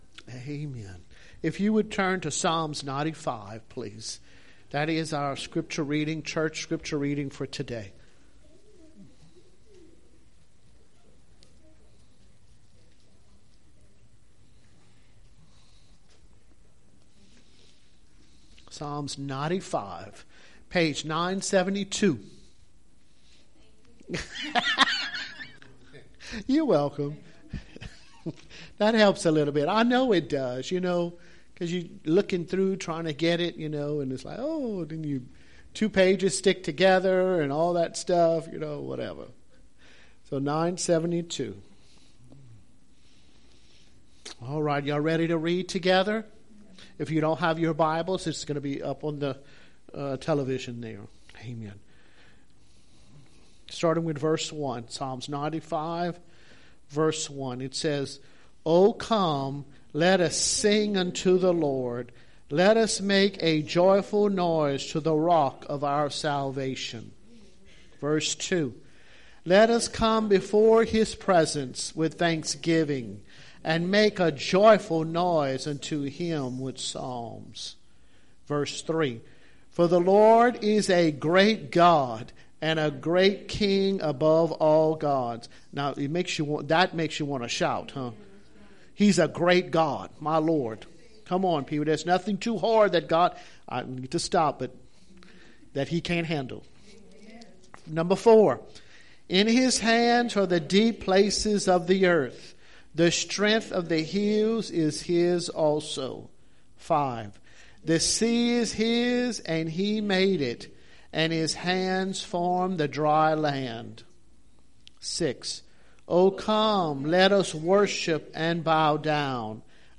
Church Sermons